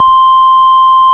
Index of /90_sSampleCDs/Keyboards of The 60's and 70's - CD1/ORG_FarfisaCombo/ORG_FarfisaCombo